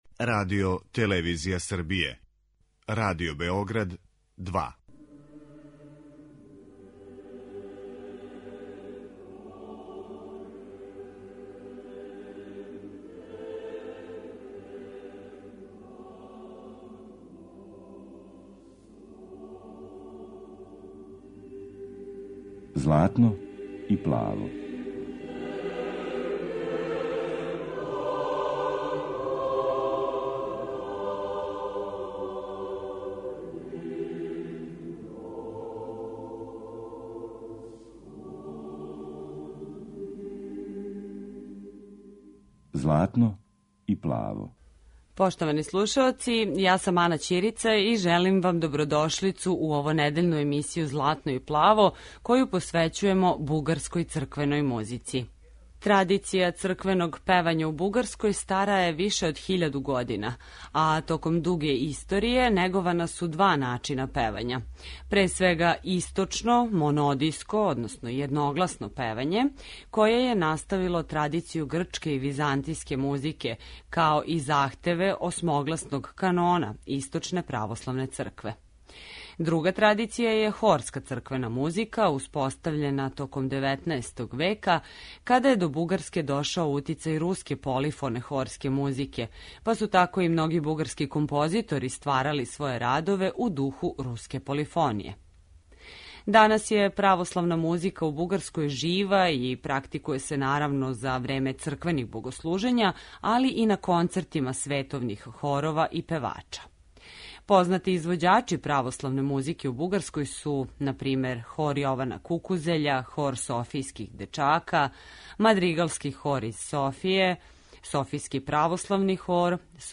Емитујемо примере раног једногласног појања уз исон, као и композиције које потписују Добри Христов и Петар Динев.
Бугарска духовна музика